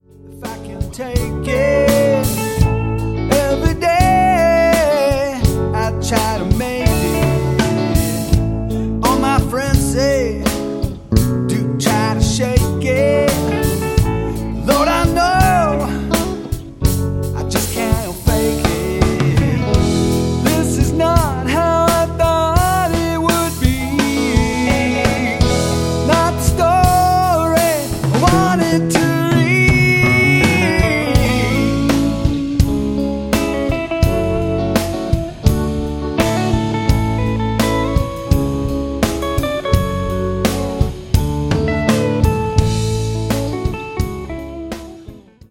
old school rock, funk and R&B
vocals
guitar
keyboards